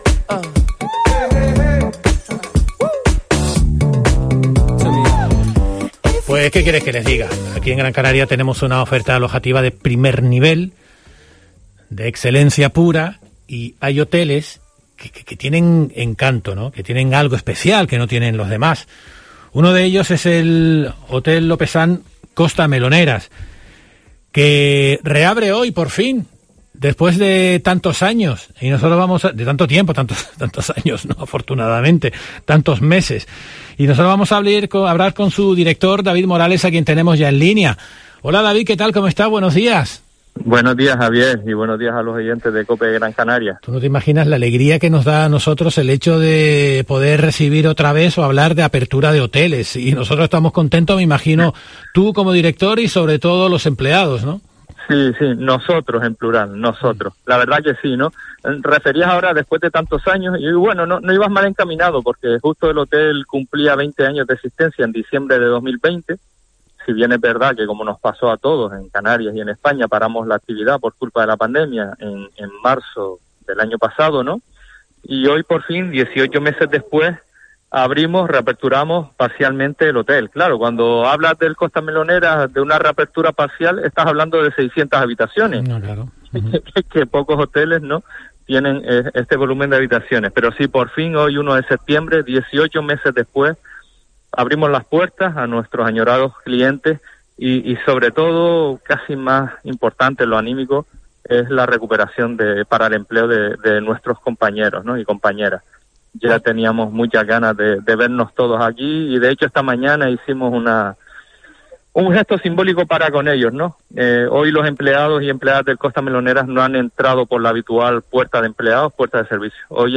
Concluye muy emocionado destacando que "la sonrisa y la alegría de todos los trabajadores con esta reapertura no tiene precio".